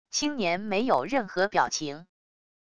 青年没有任何表情wav音频